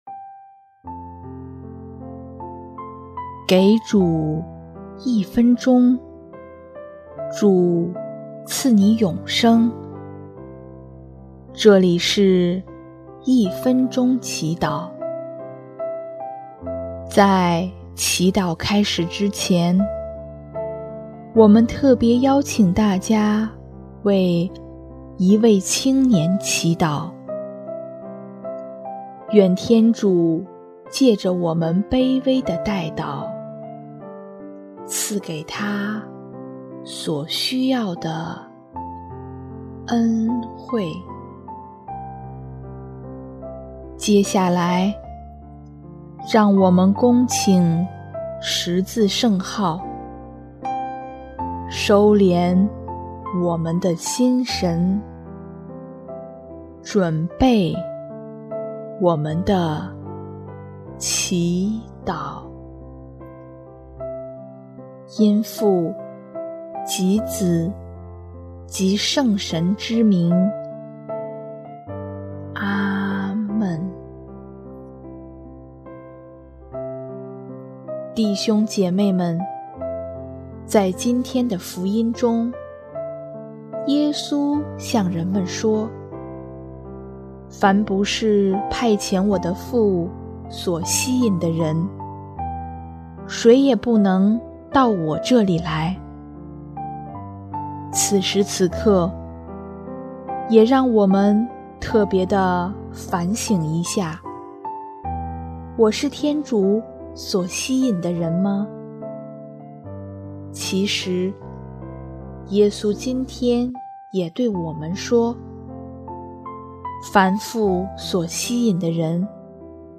音乐：第三届华语圣歌大赛参赛歌曲《阿爸天父》（一位青年：内在的治愈）